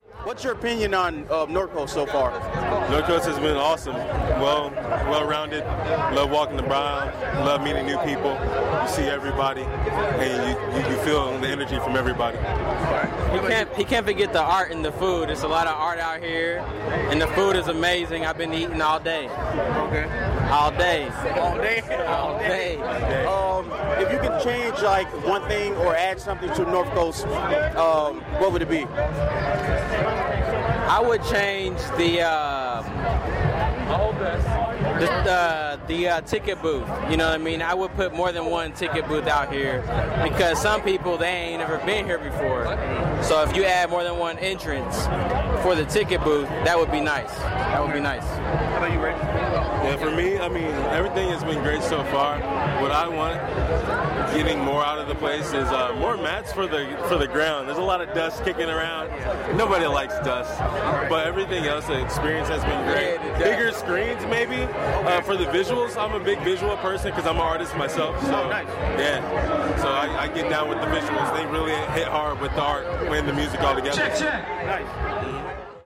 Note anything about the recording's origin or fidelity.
This brand new series will feature raw and straight to the point interviews with fans who toughed it out to get the coveted front row guardrail spot for their favorite artist’s sets.